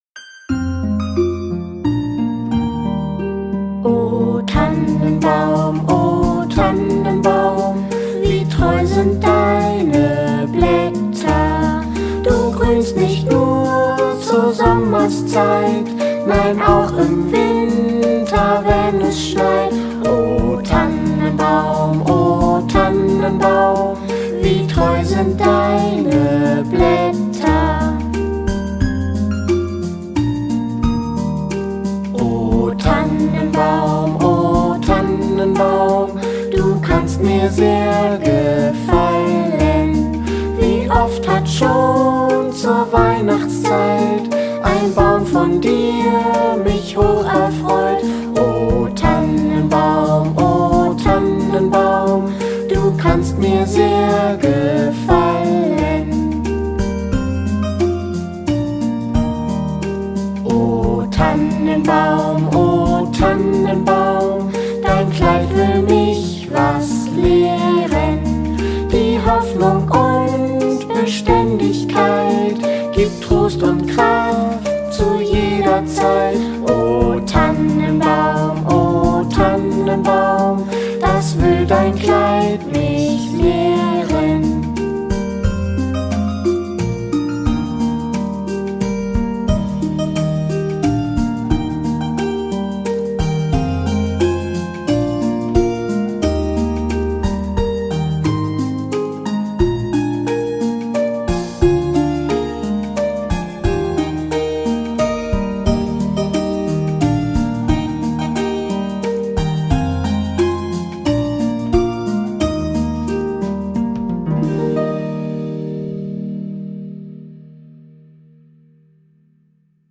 (chant traditionnel de noël allemand)